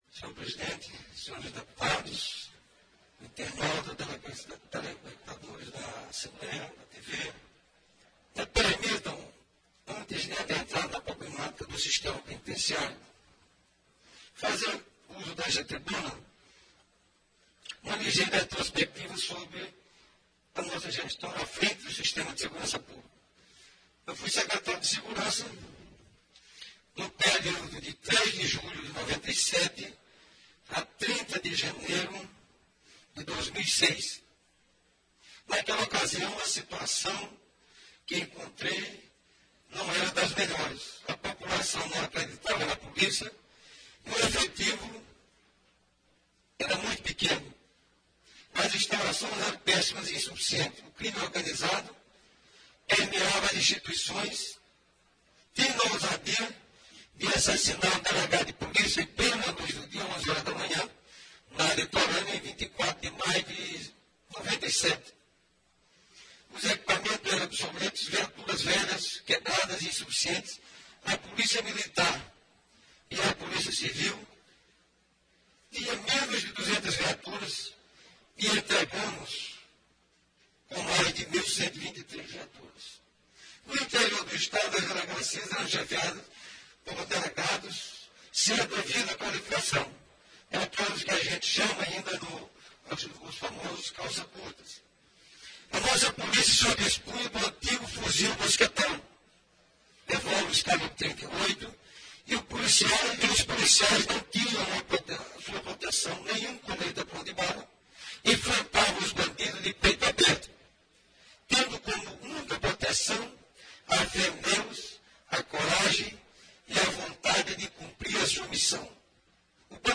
Pronunciamento do deputado Raimundo Cutrim do dia 24 de fevereiro de 2011, onde o mesmo fala sobre os problemas do sistema de segurança e prisional brasileiro, em particular do Maranhao.
Tem também apartes de outros deputados.